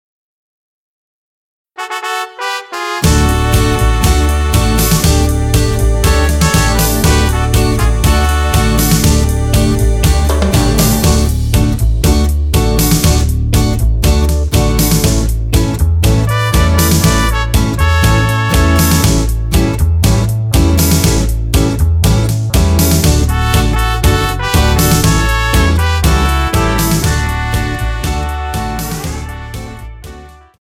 --> MP3 Demo abspielen...
Tonart:D ohne Chor